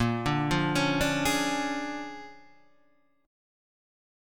A#7#9 chord